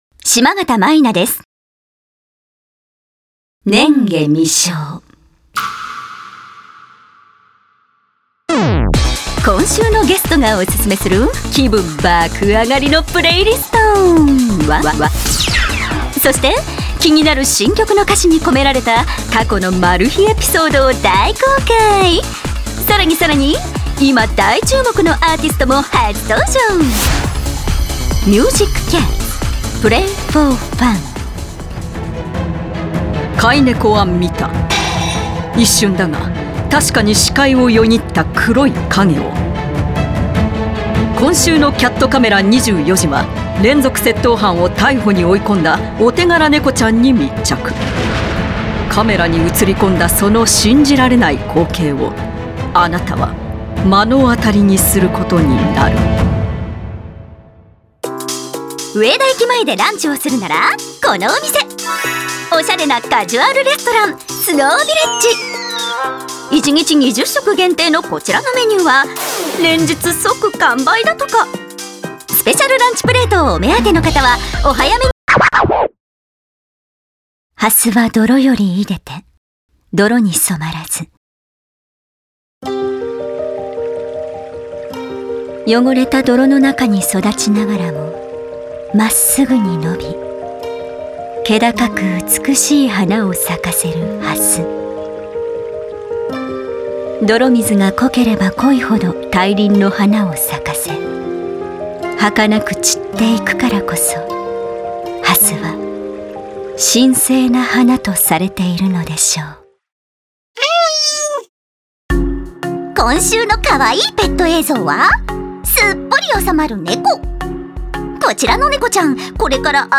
ボイスサンプル
ナレーション「拈華微笑」